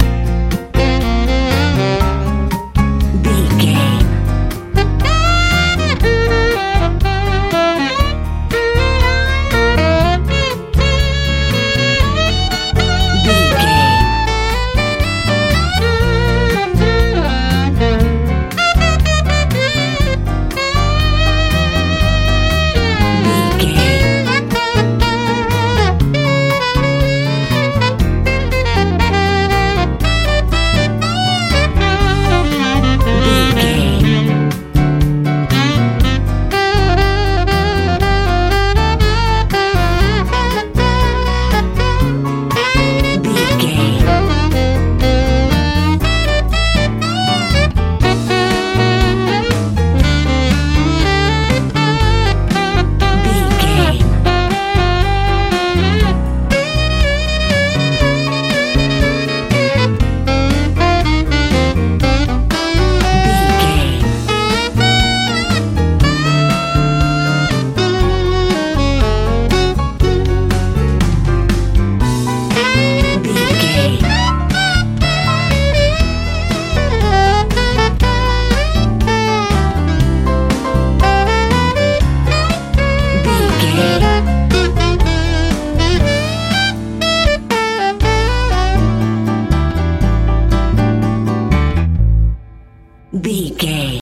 Ionian/Major
E♭
groovy
funky
playful
saxophone
piano
bass guitar
drums
lively
driving
energetic
hopeful